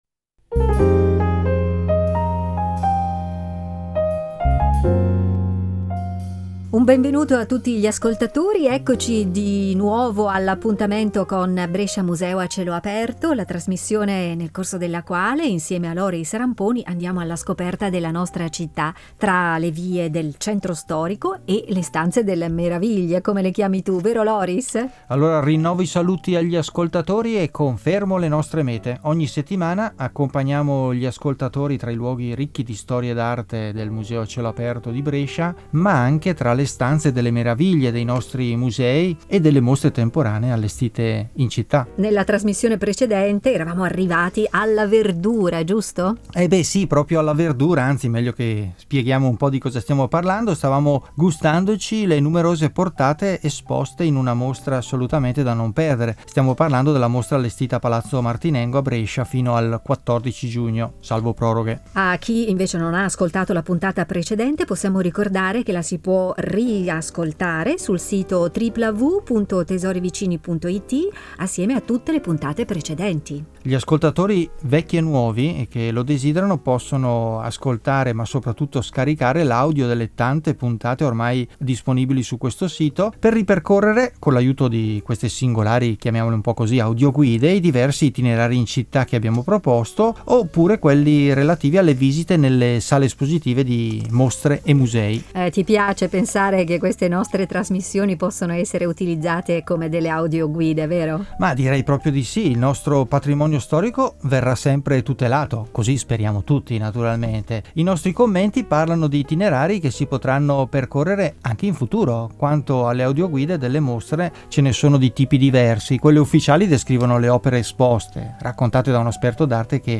audio-guida e itinerari per passeggiare tra i musei e le mostre